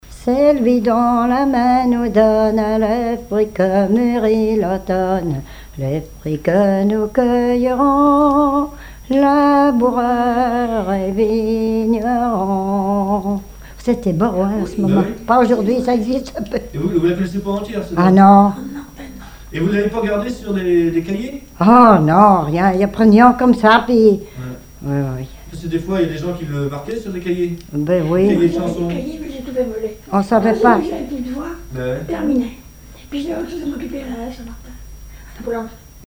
Bribes de chansons ou cantiques
Pièce musicale inédite